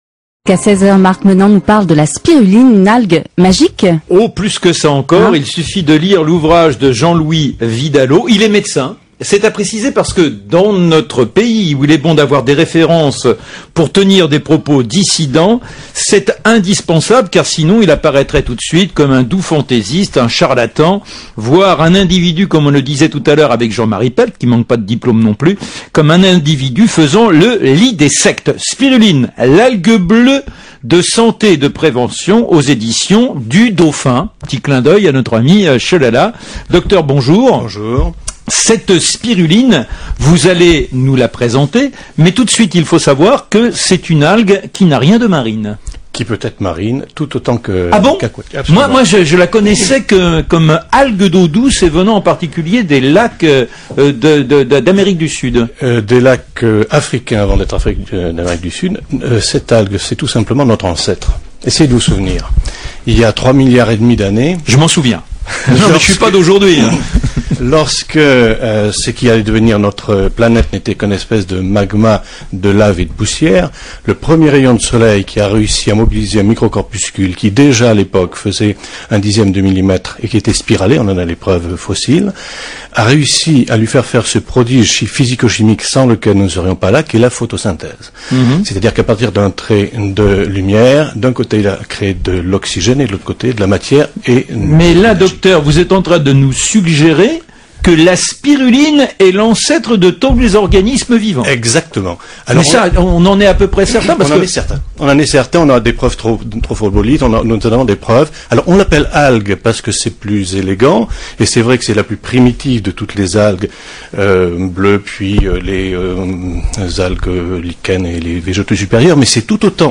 Son interview sur Europe 1 : Cette intervention fait suite à l'édition de son livre dédié à la spiruline, l'un des plus complets à ce jour.